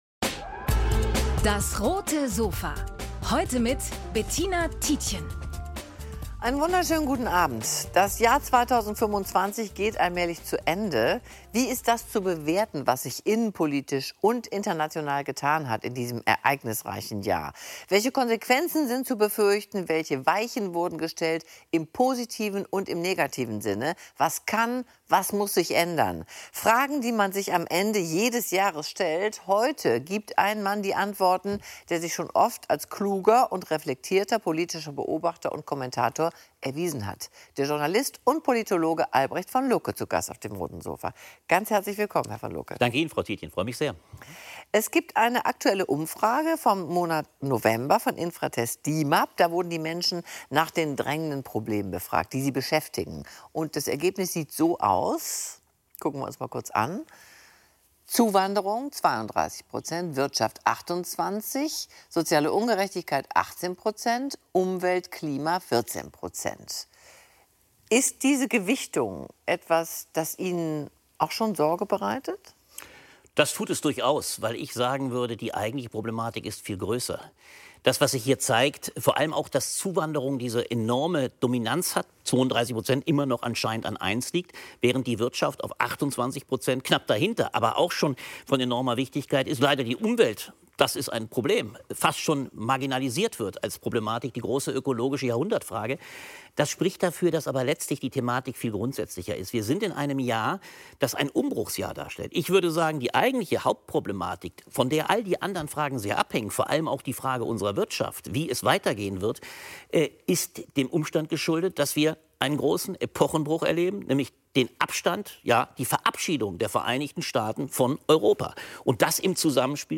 Der Jurist, Journalist und Politologe blickt gemeinsam mit Bettina Tietjen auf das politische Jahr 2025 zurück.